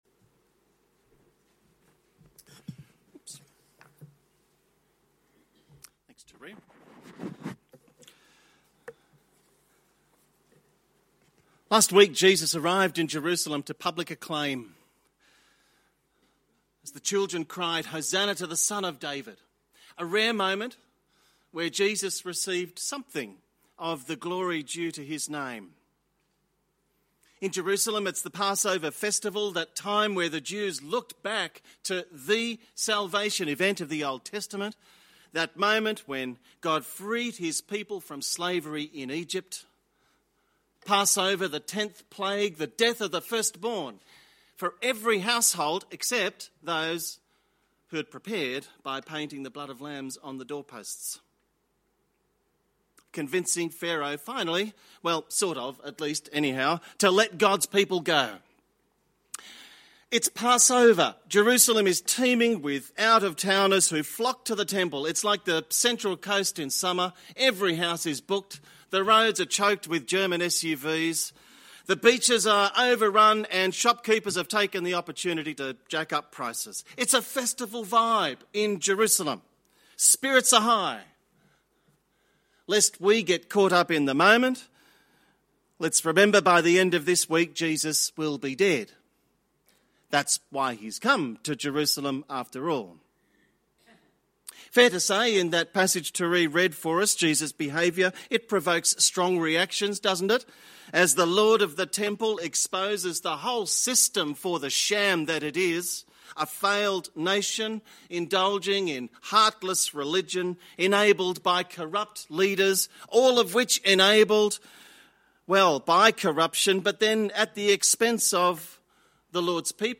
Sermons by St Judes Anglican Church